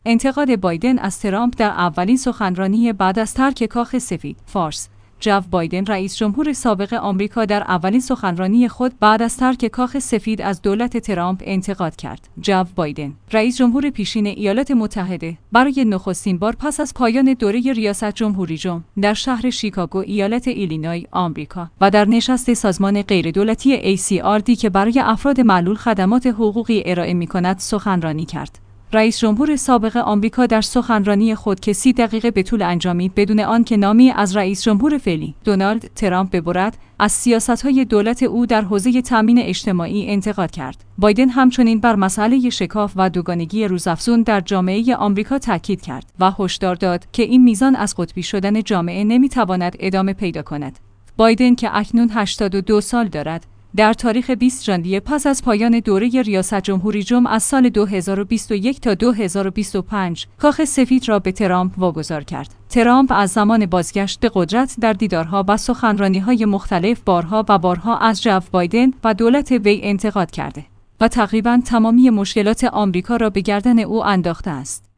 انتقاد بایدن از ترامپ در اولین سخنرانی بعد از ترک کاخ سفید